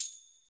Index of /90_sSampleCDs/Roland - Rhythm Section/PRC_Latin 2/PRC_Tambourines
PRC TAMBFI0A.wav